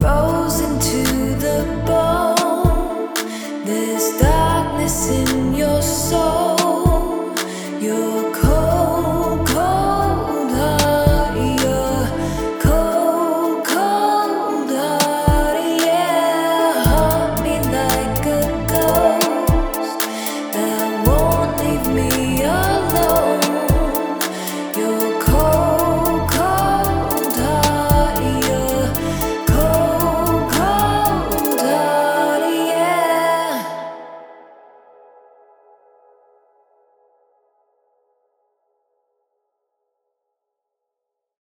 I created a short demo track in Soundtrap using a vocal loop and some instruments for this article, so you can hear the difference before and after mastering.
Here’s the original, unmastered track:
Unmastered
Mastering-Presets-Demo-no-mastering.mp3